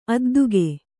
♪ adduge